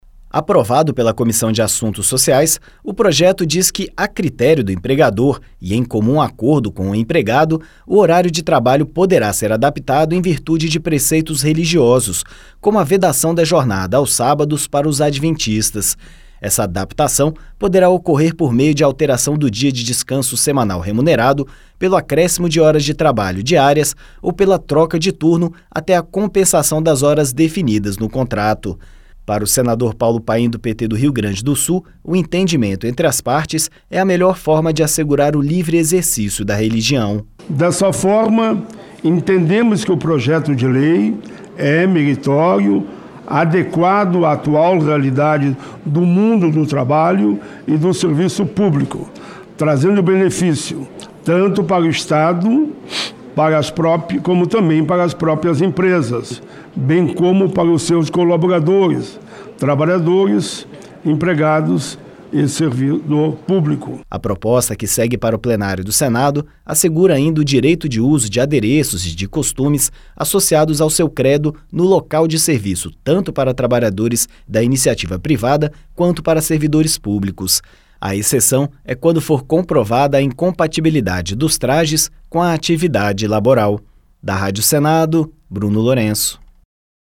O relator, Paulo Paim (PT-RS), diz que o entendimento entre empregado e patrão, previsto no texto, é a melhor forma de assegurar o livre exercício da religião.